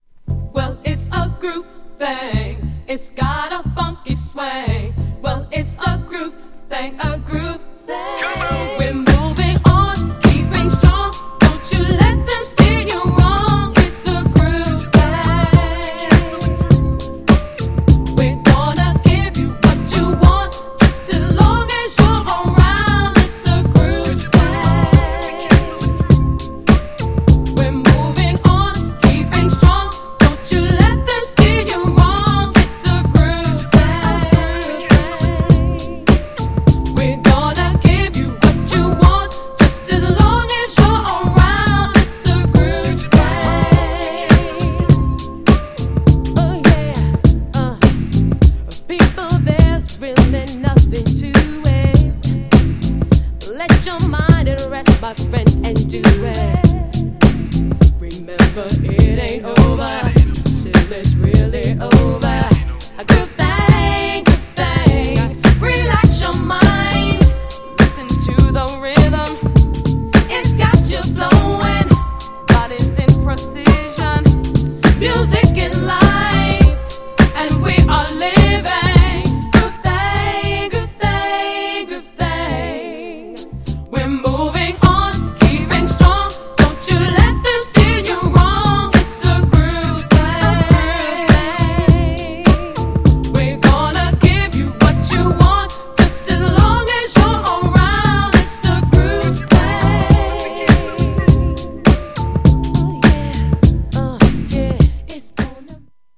ダンサブルなオリジナルも収録!! 極上の90's R&B!!
GENRE R&B
BPM 101〜105BPM
GROOVYなR&B
ちょいハネ系 # グルーヴ感有 # メロディアスR&B # 女性デュオR&B # 歌心を感じる